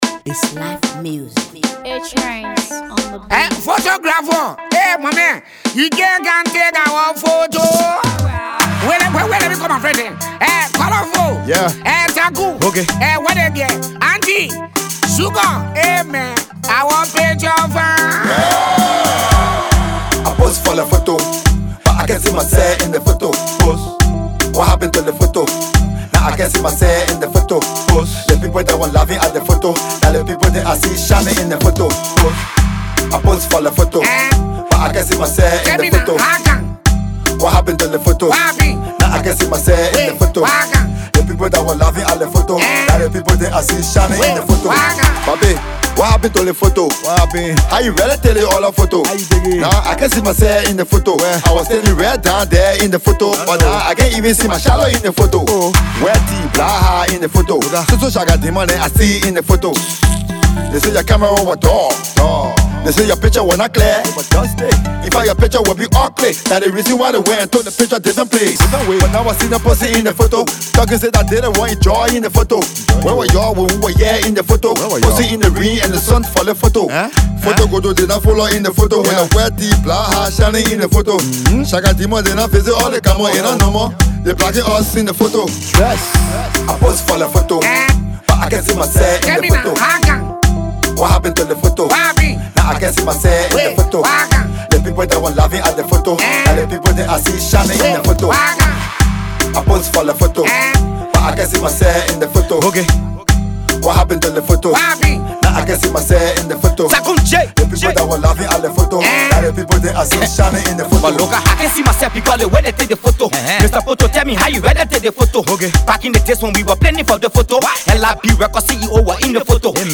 / Hip-Co / By
A lot of talks on this one enjoy!